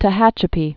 (tə-hăchə-pē)